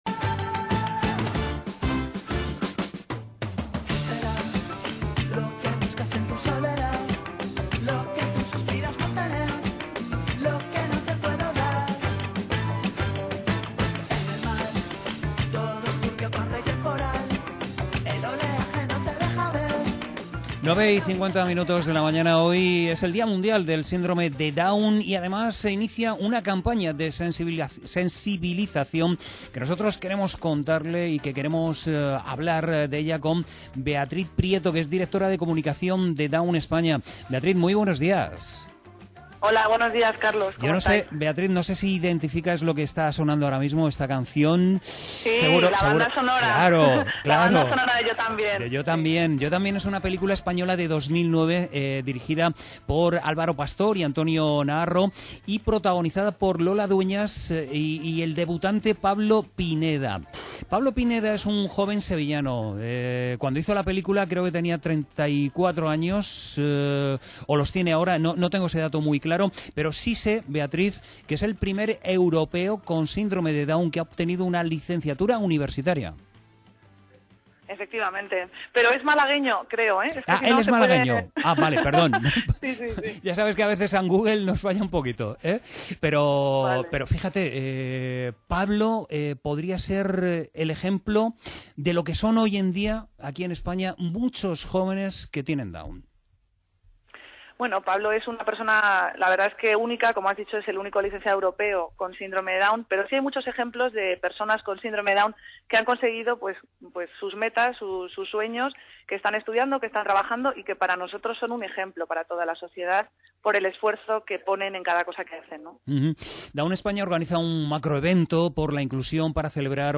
entrevista2103.mp3